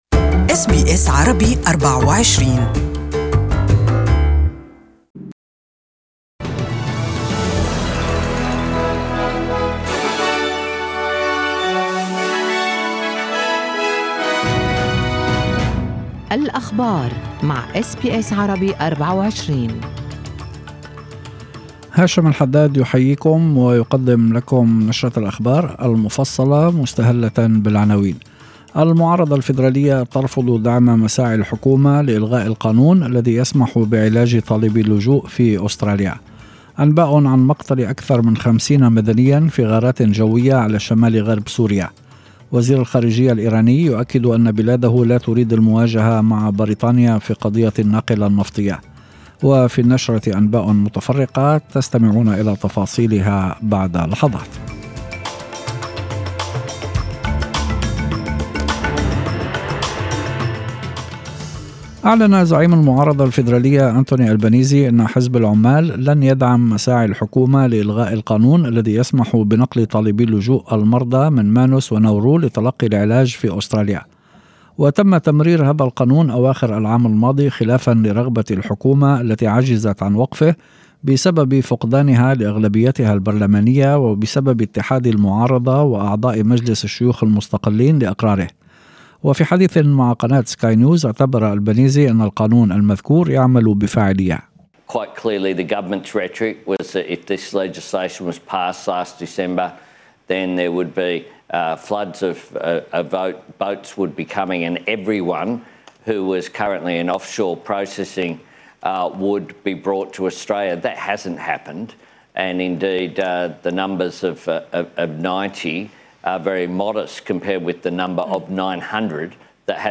Evening News: Anthony Albanese won't support government plan to cancel Medvec law